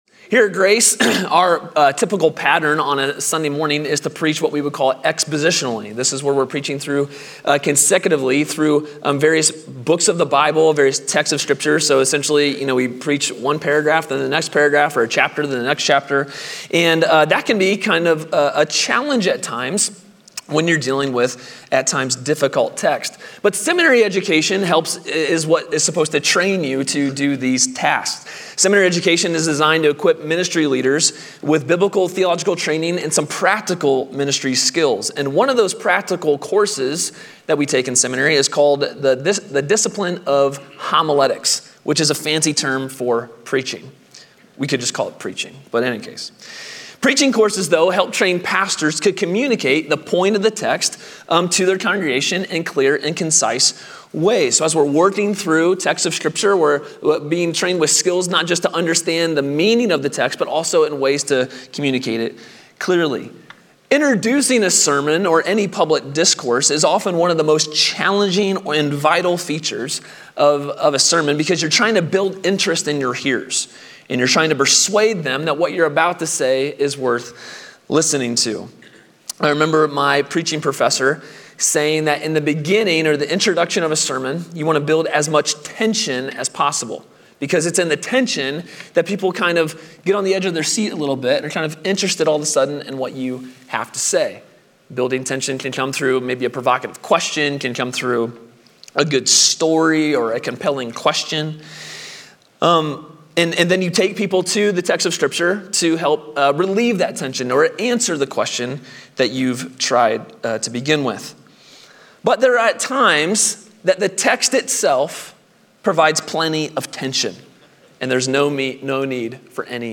A sermon from the series "Something to Believe In." The Word of God is able to accomplish the work of God.